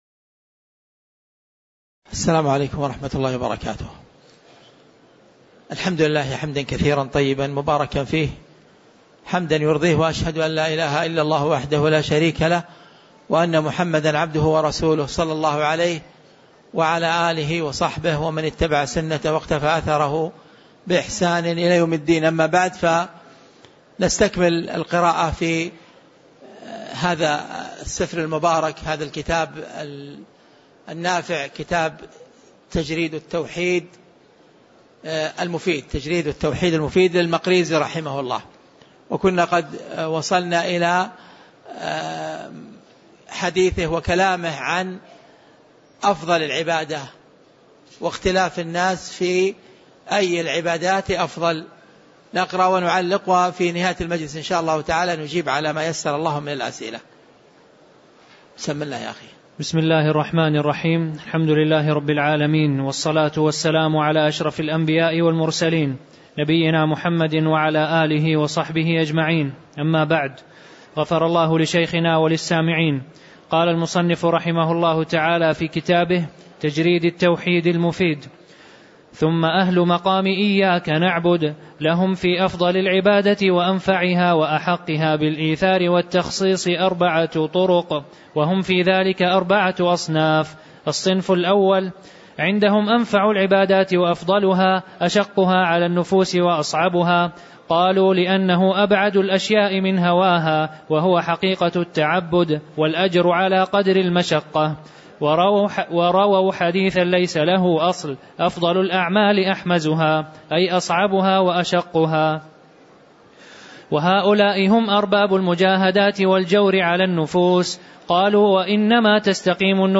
تاريخ النشر ٢٤ ربيع الثاني ١٤٣٩ هـ المكان: المسجد النبوي الشيخ